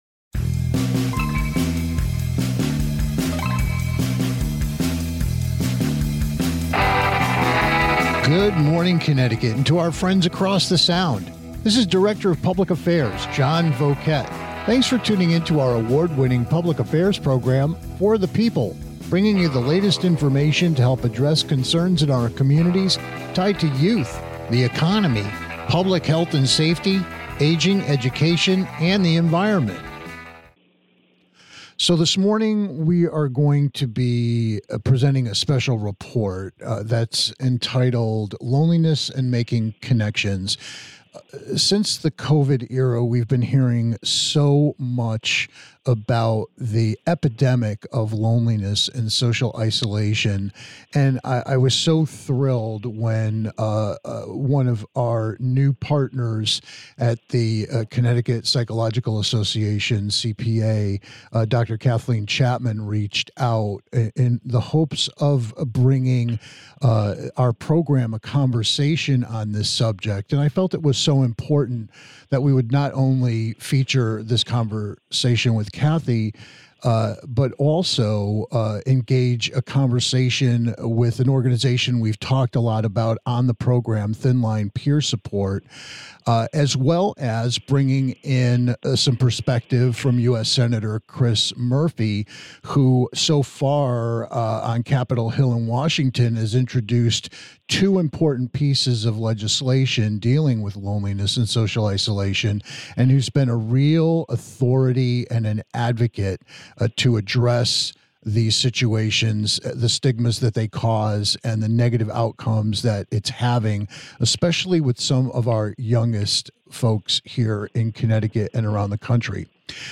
This week we're reacting to the epidemic of loneliness and social isolation with a Special Report featuring three guests all confronting and dealing with the issue in their own ways.